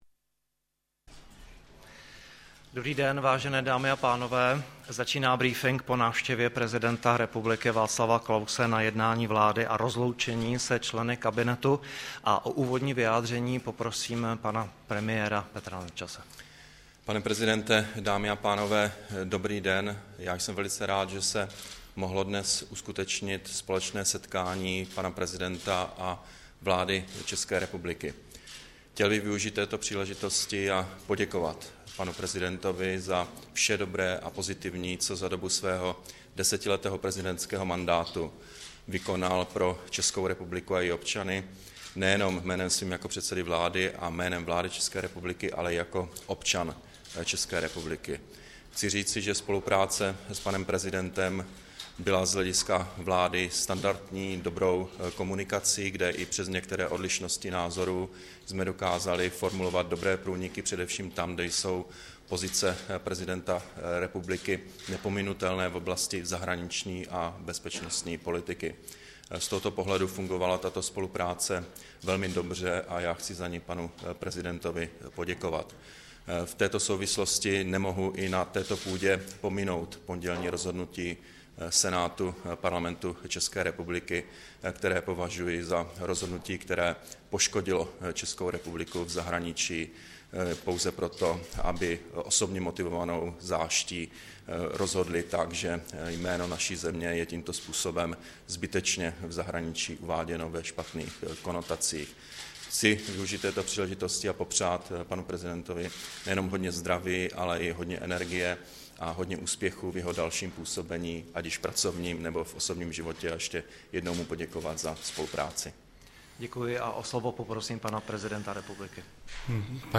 Tiskový brífink po návštěvě prezidenta Václava Klause na jednání vlády, 6. března 2013